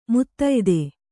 ♪ muttayde